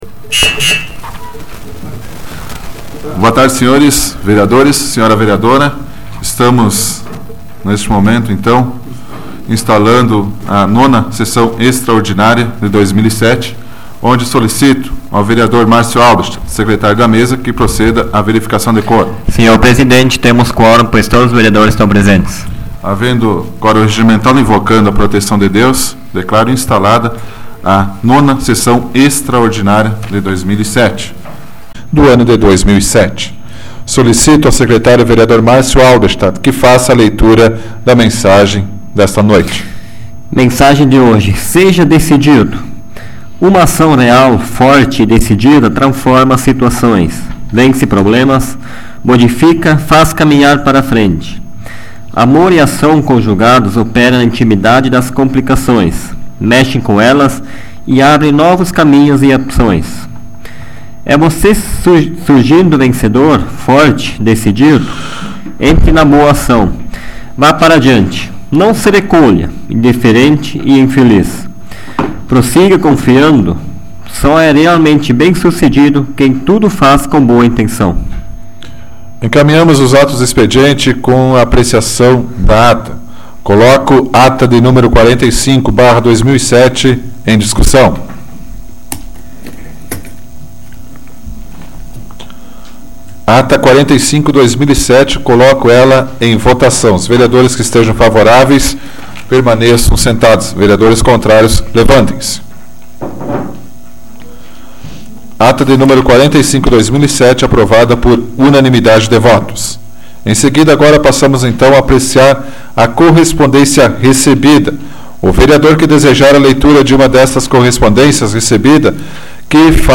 Áudio da 106ª Sessão Plenária Ordinária da 12ª Legislatura, de 12 de novembro de 2007